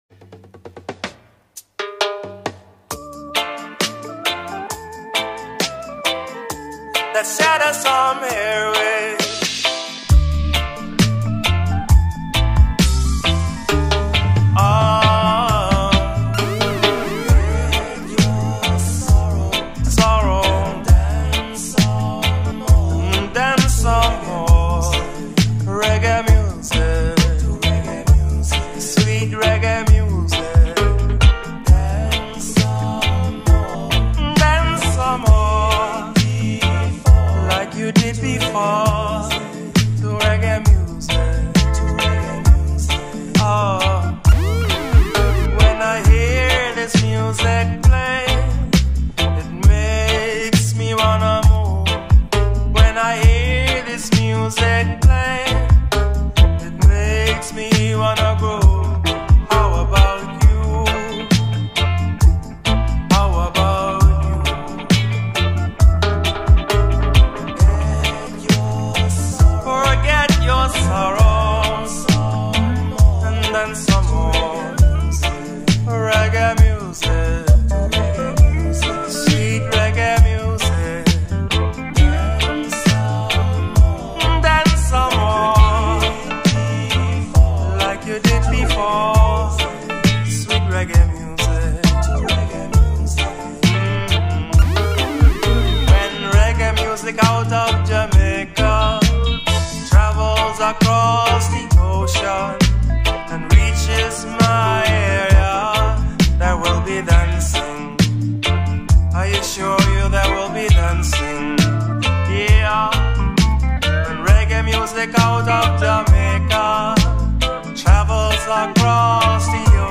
Roots Reggae & Dub Mix, vinyl only.